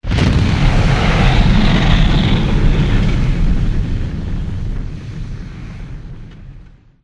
fire2.wav